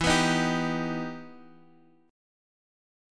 EmM7 chord